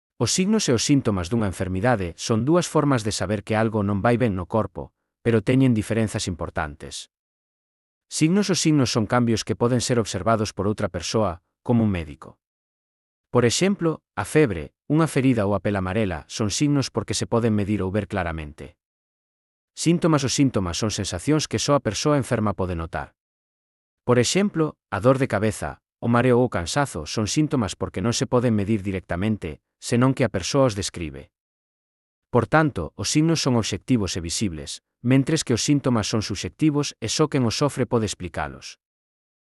Elaboración propia coa ferramenta Narakeet. Transcrición de texto a audio (CC BY-SA)